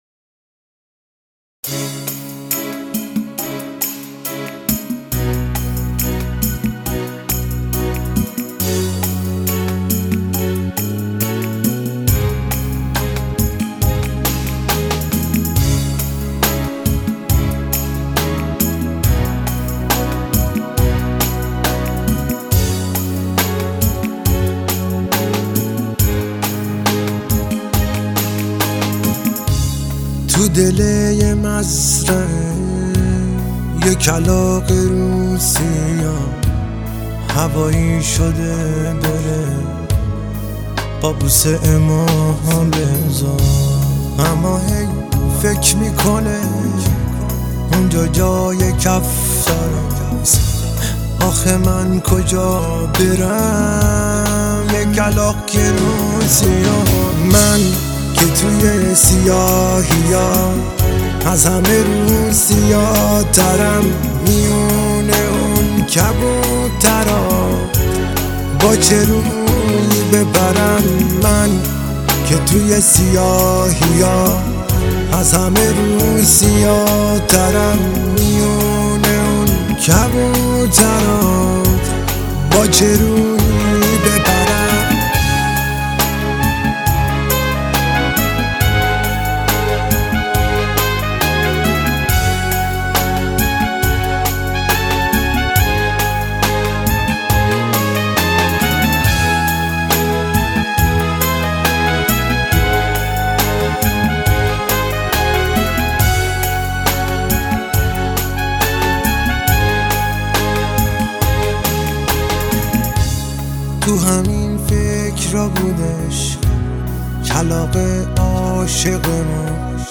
نوحه
نوحه سوزناک
با صدای دلنشین